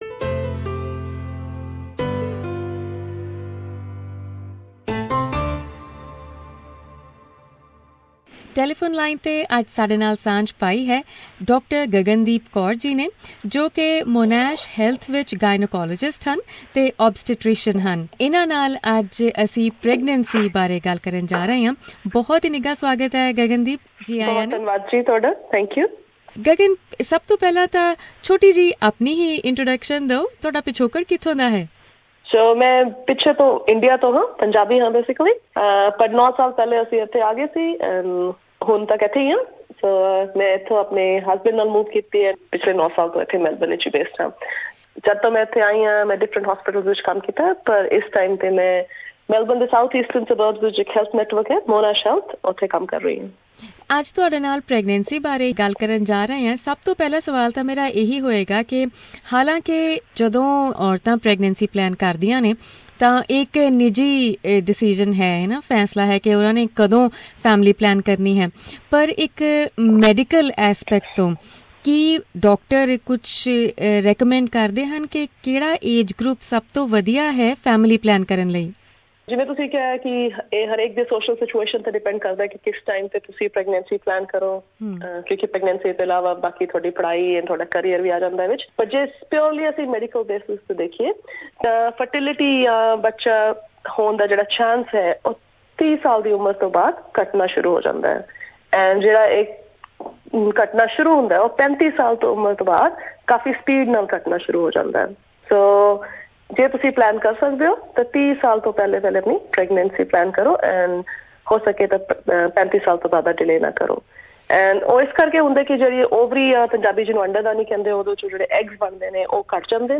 She reminds us that it is important to look after the mother to avoid her falling into post natal depression or anxiety. Listen to the full interview here.